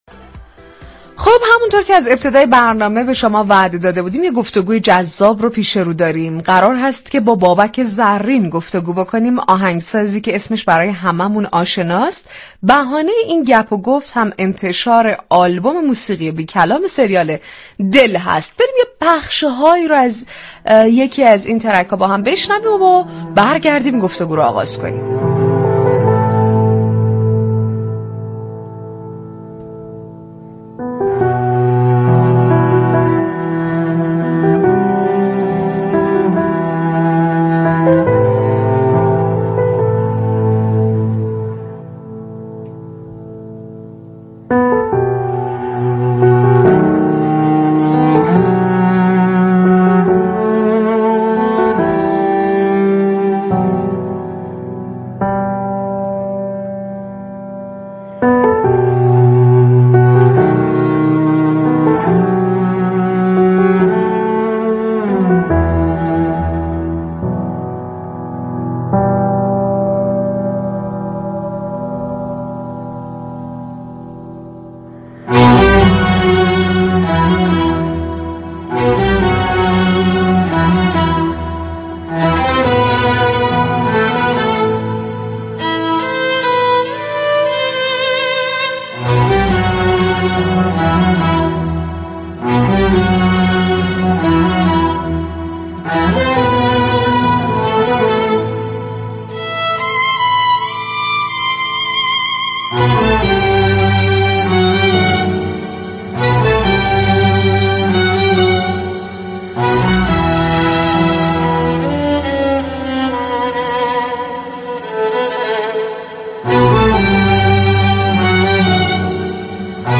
بابك زرین در گفتگو با رادیو صبا از اجرای كنسرت زنده اش با عنوان عاشقانه های بابك زرین خبر داد.